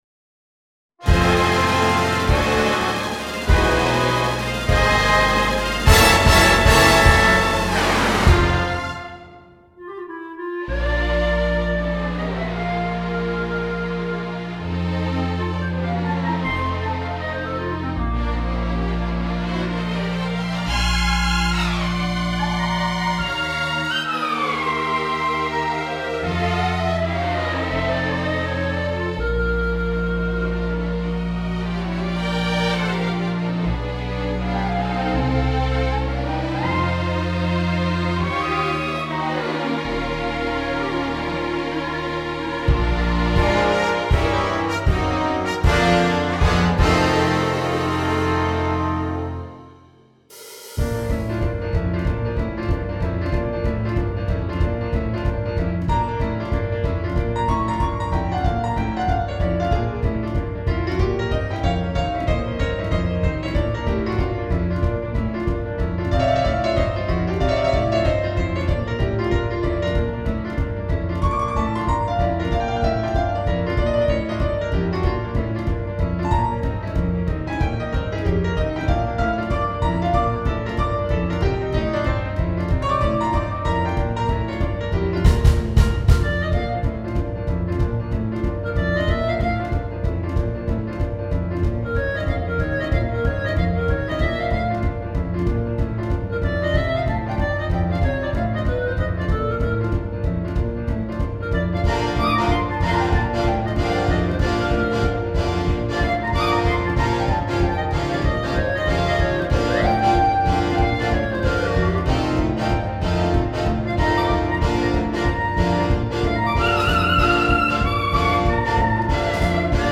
This file contains a performance and accompaniment.